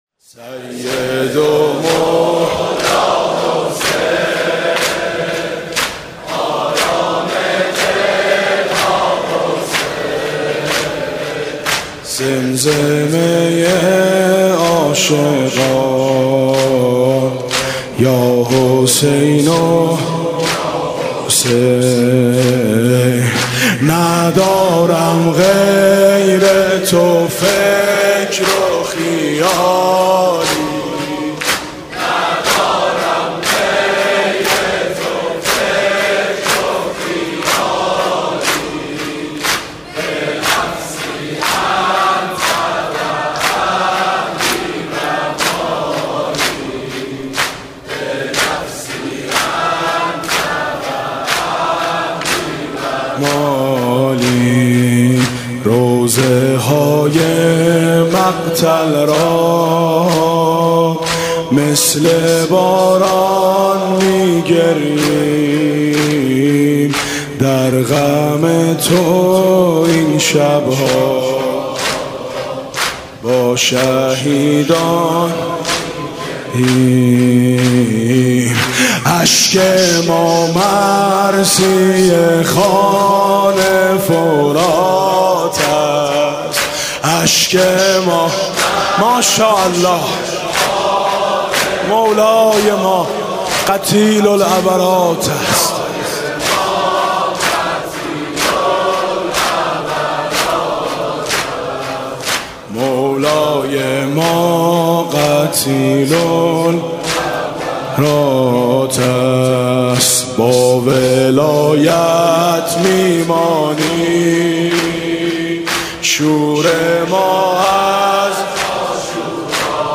«محرم 1396» (شب یازدهم) دم پایانی: سید و مولا حسین، آرام دل ها حسین